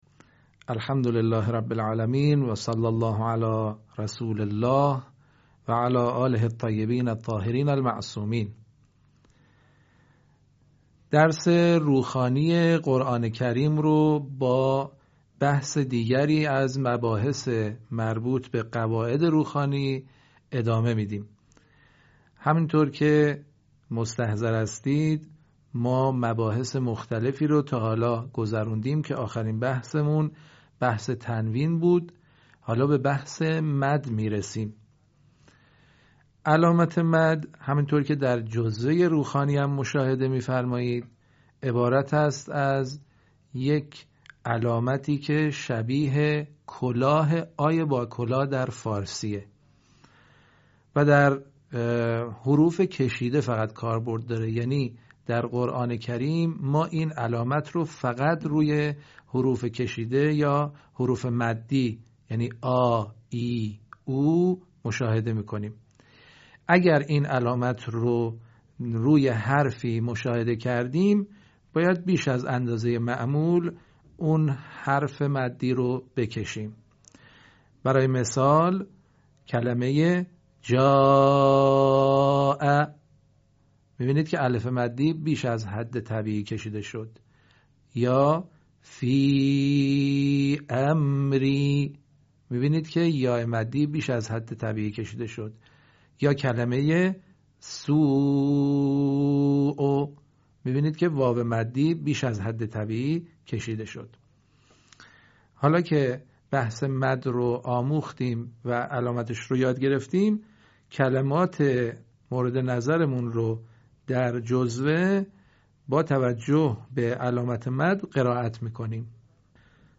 به همین منظور مجموعه آموزشی شنیداری (صوتی) قرآنی را گردآوری و برای علاقه‌مندان بازنشر می‌کند.
آموزش قرآن